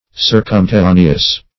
Search Result for " circumterraneous" : The Collaborative International Dictionary of English v.0.48: Circumterraneous \Cir`cum*ter*ra"ne*ous\, a. [Pref. circum- + L. terra earth.]
circumterraneous.mp3